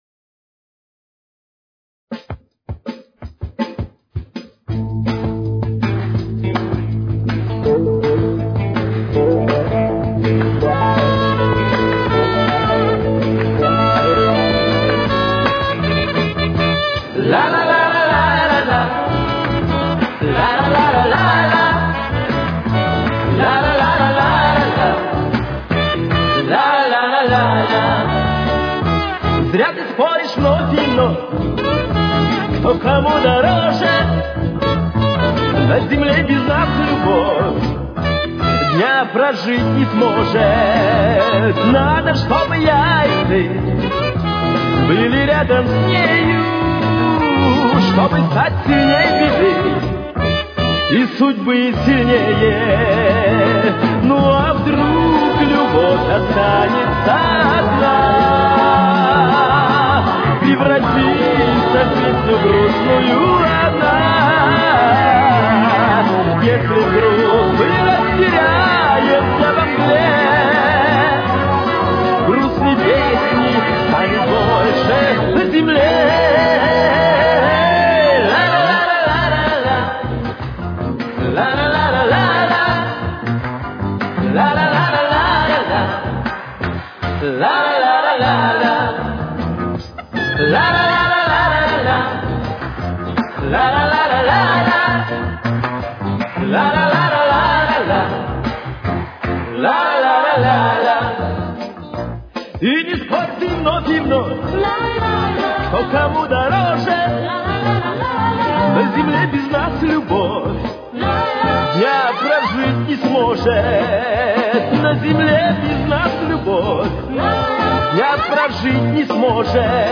Тональность: Ля мажор. Темп: 162.